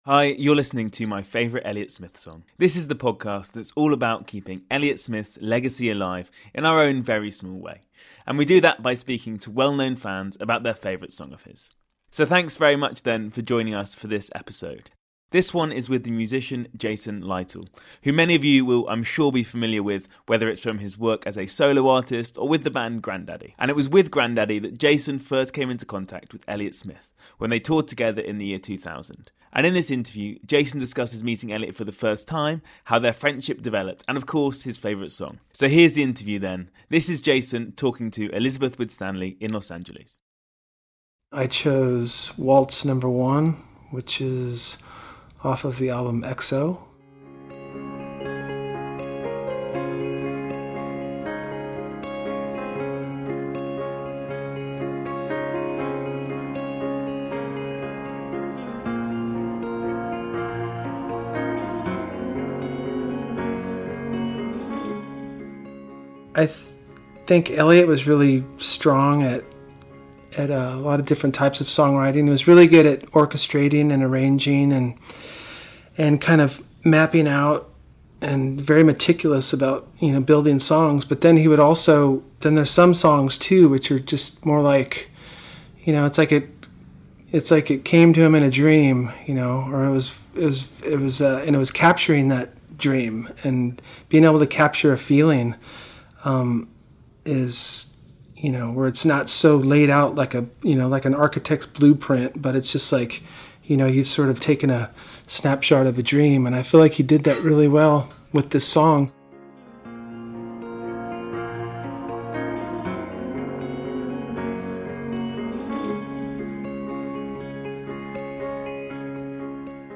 Our guest for episode 14 is the musician Jason Lytle.
In this interview, Jason discusses the moment he met Elliott for the first time, how their friendship developed and, of course, his favourite song.